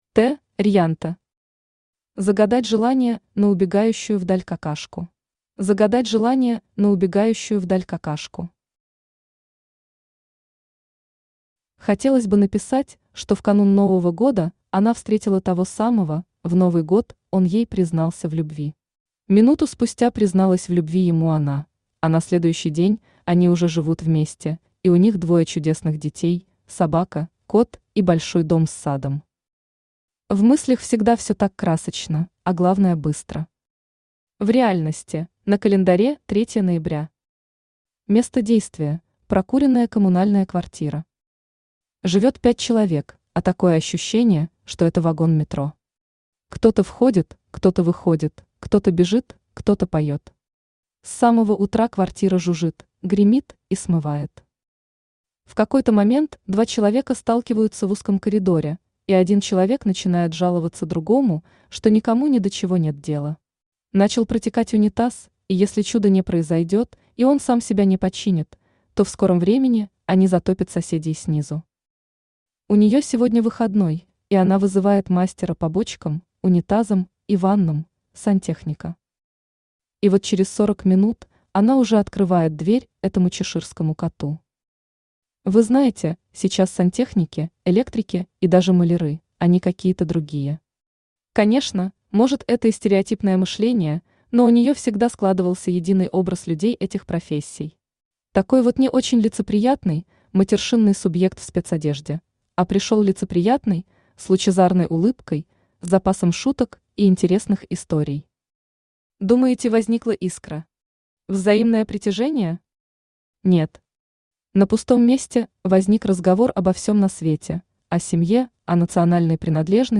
Aудиокнига Загадать желание на убегающую вдаль какашку Автор Т. Рьянто Читает аудиокнигу Авточтец ЛитРес.